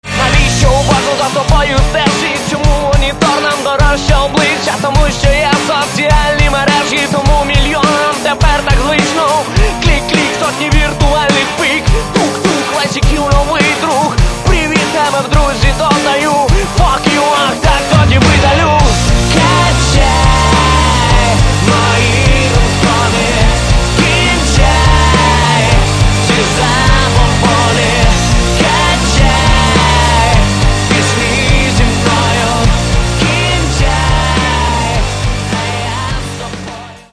Catalogue -> Rock & Alternative -> Light Rock